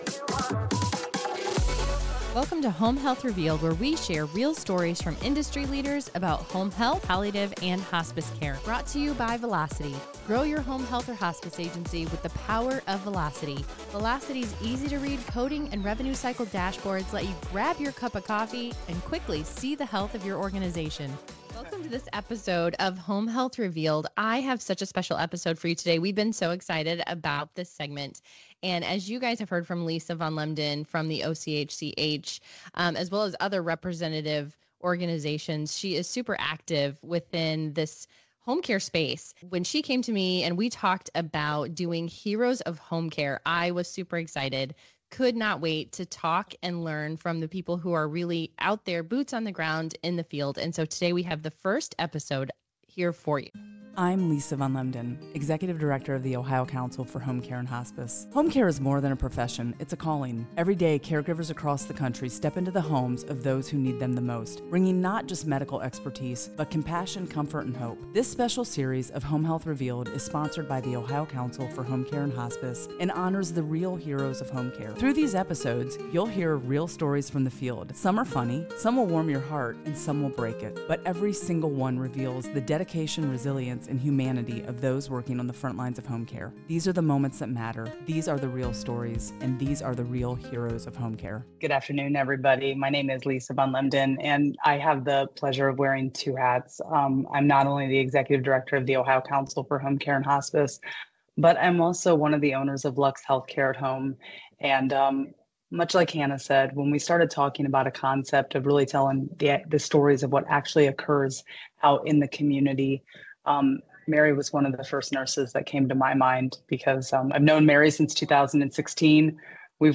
Home Health Revealed (+Palliative and Hospice) Velocity as a Saas Solution, Interview